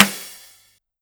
drum-hitnormal.wav